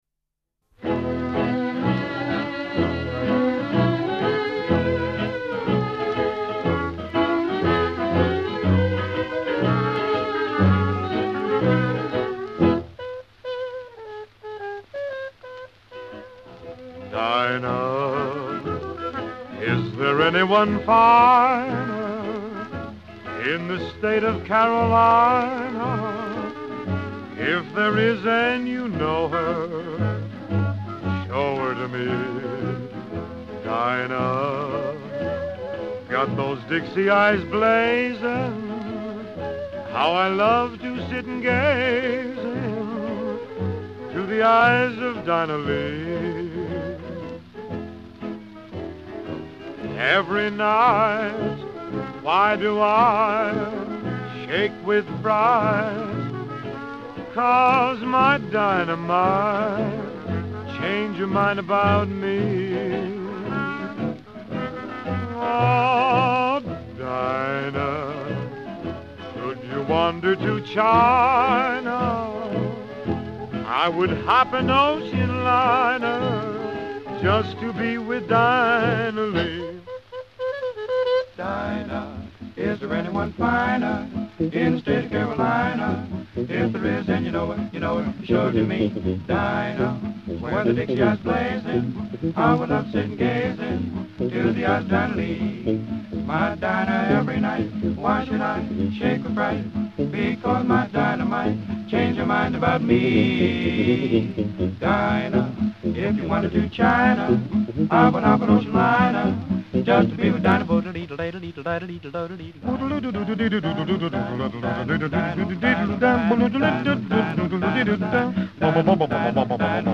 velluto allo stato puro!!